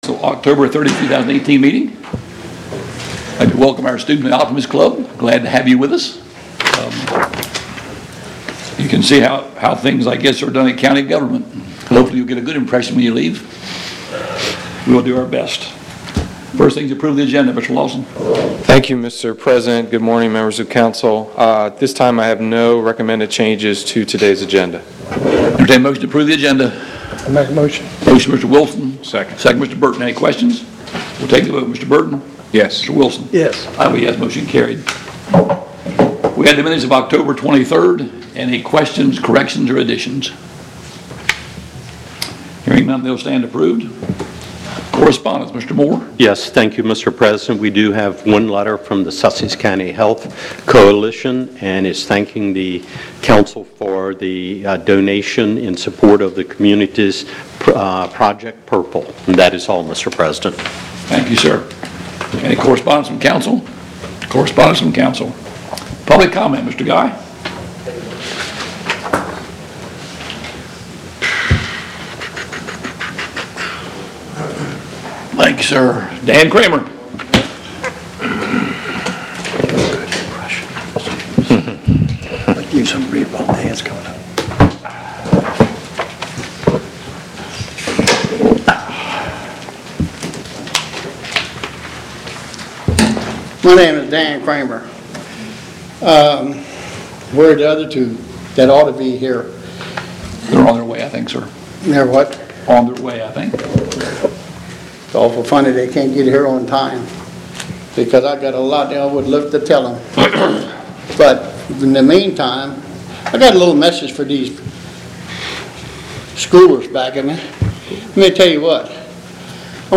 County Council Meeting | Sussex County
Meeting type: County Council